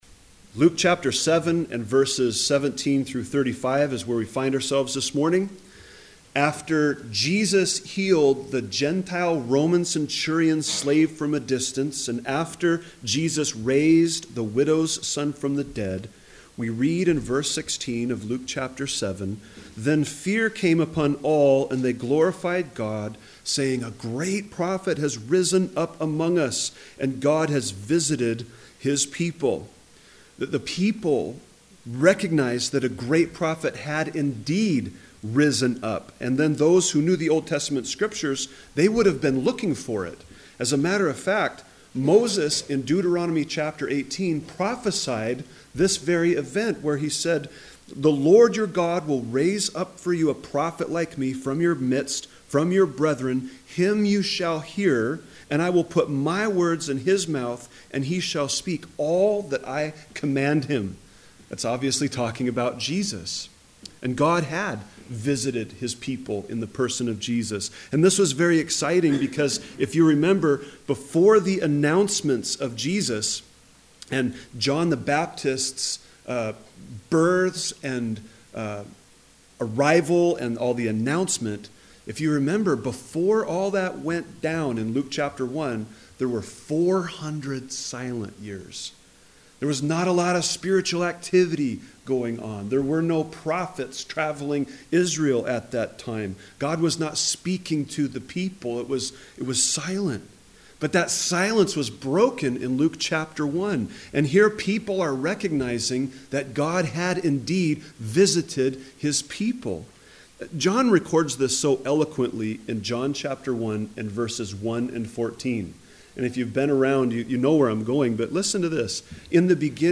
A message from the series "Gospel of Luke."